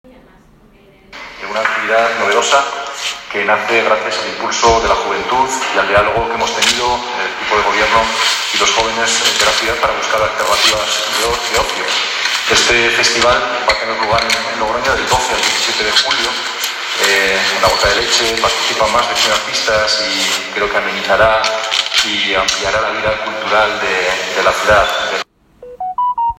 El alcalde de Logroño presenta el nuevo festival de arte joven ‘Barullo Fest 21’, que se celebrará del 12 al 17 de julio en el entorno de La Gota de Leche - Logroño